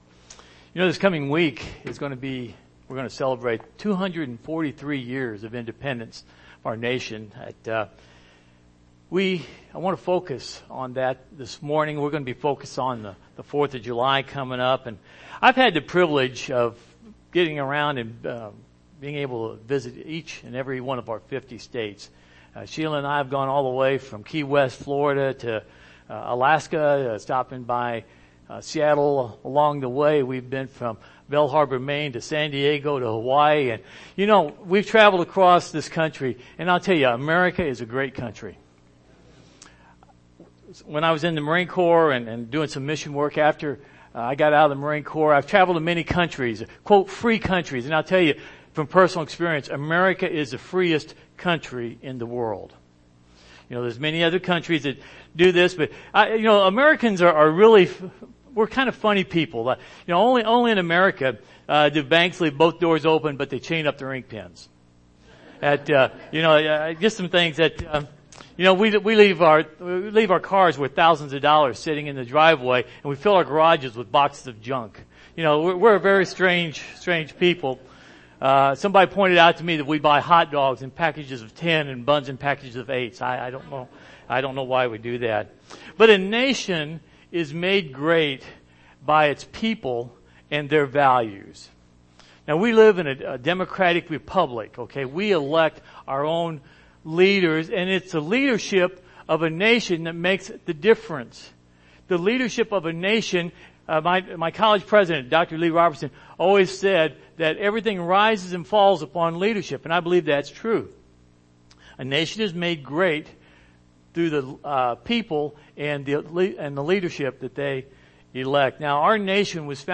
2 Chronicles 7:14 Service Type: Morning Service Download Files Bulletin « A Desperate Prayer for a Troubled Nation Refocus